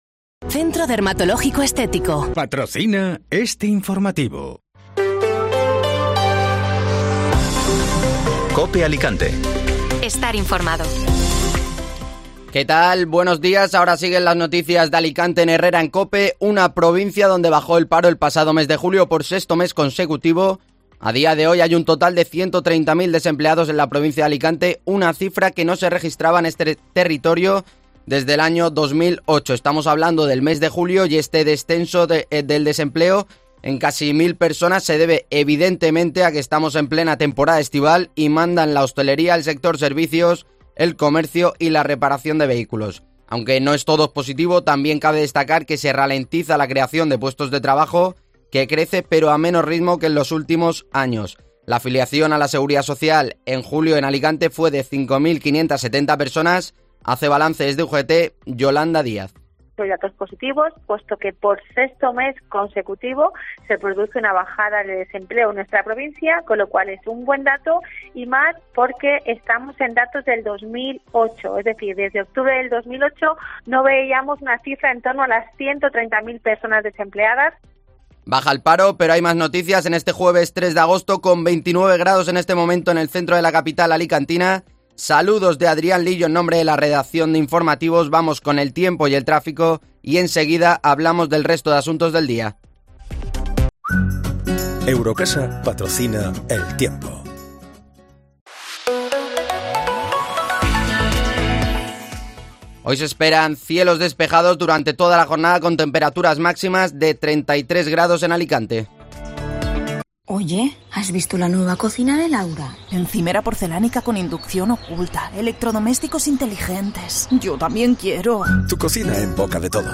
Informativo Matinal (Jueves 3 de Agosto)